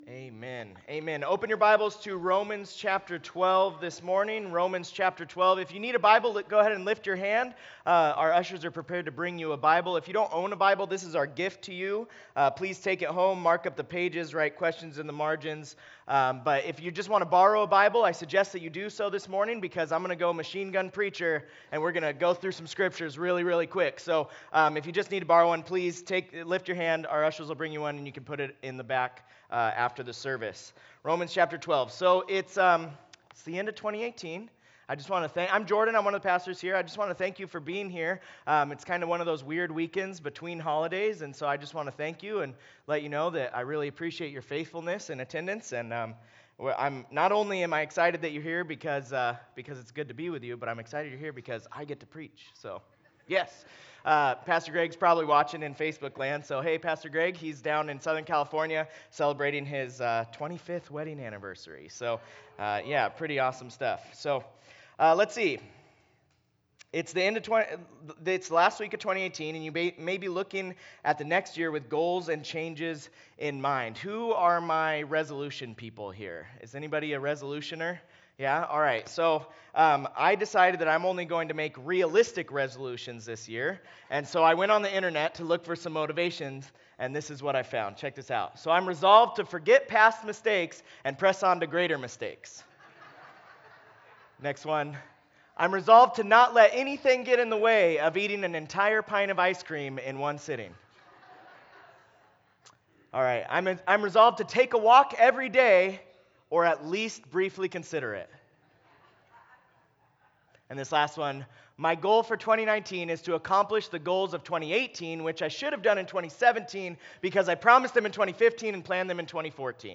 Service Type: Weekend Services